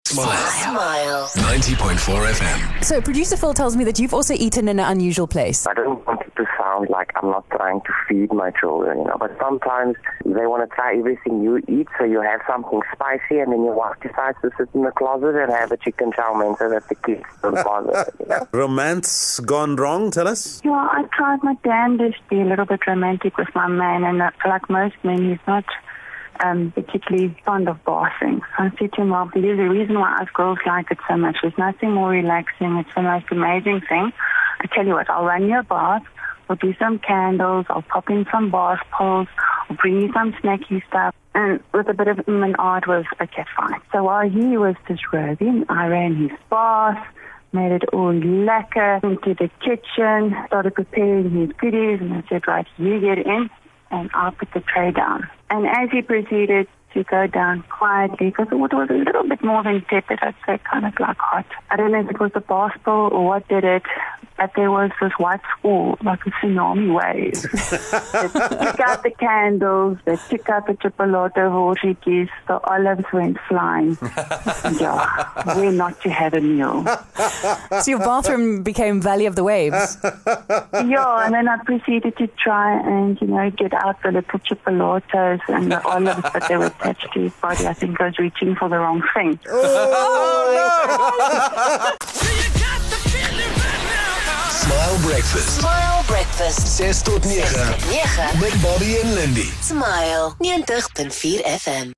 After finding out that a small percentage of people eat in the bath, we got a call from a parent who has a special place to avoid sharing food with his kid and also someone who has experience in eating in the bath and why it's a bad idea.